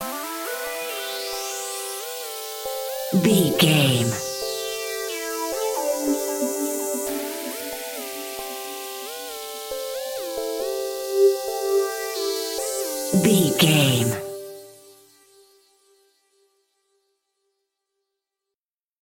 Epic / Action
Fast paced
Mixolydian
aggressive
dark
futuristic
energetic
drum machine
synthesiser
breakbeat
synth leads
synth bass